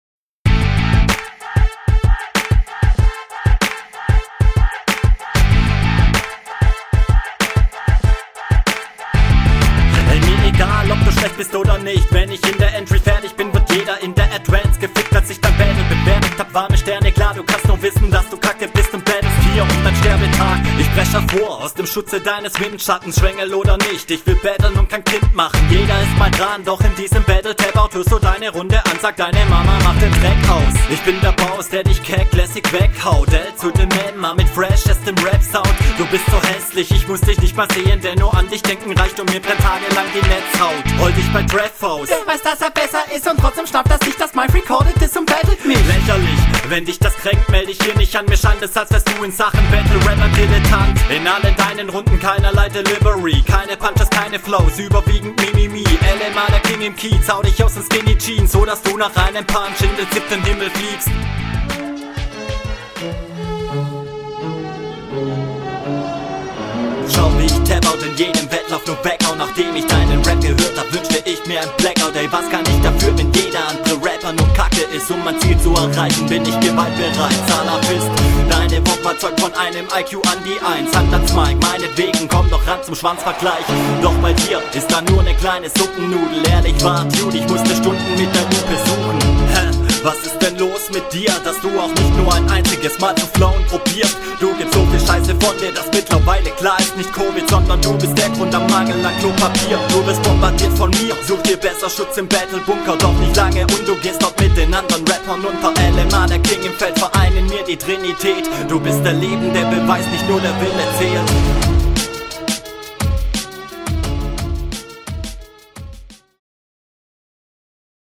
Okay der beat ballert richtig los, mit den streichern die reinkommen hämmert dein Flow auch …